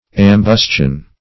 Search Result for " ambustion" : The Collaborative International Dictionary of English v.0.48: Ambustion \Am*bus"tion\ ([a^]m*b[u^]s"ch[u^]n; 106), n. [L. ambustio.]